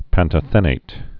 (păntə-thĕnāt, păn-tŏthə-nāt)